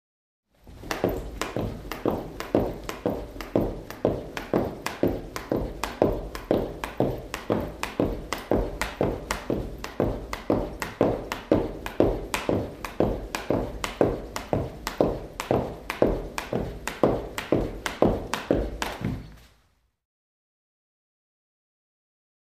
Cardiovascular Exercise; Jumping Rope. Steady.